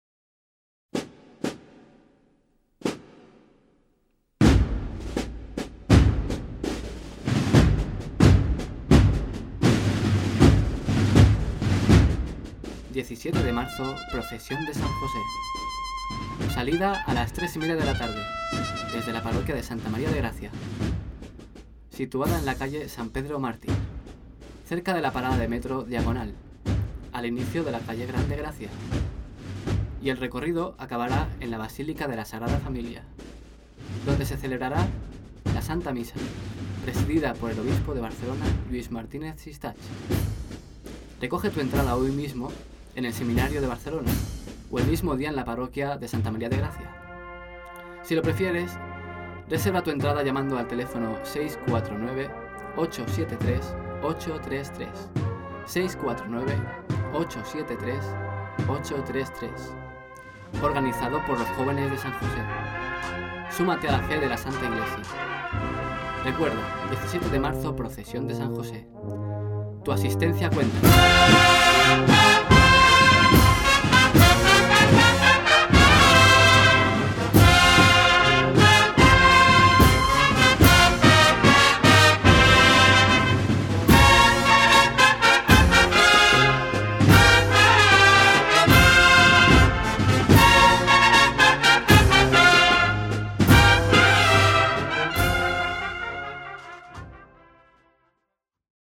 Procesión San Jose cuña radio completa